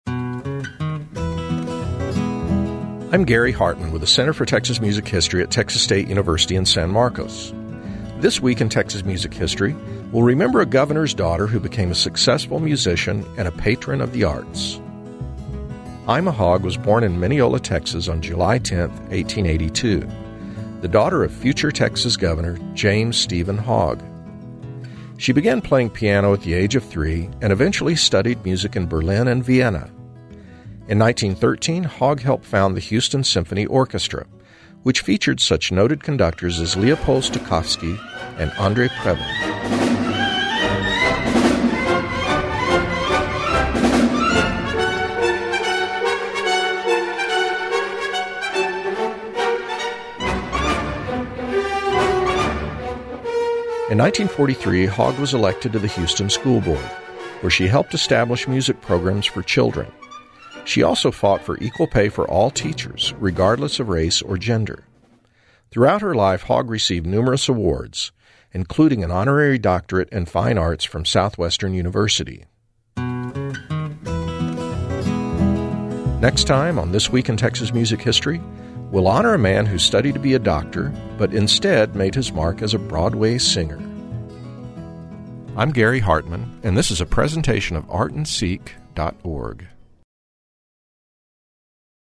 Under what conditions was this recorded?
You can also hear This Week in Texas Music History on Friday on KXT and Saturday on KERA radio.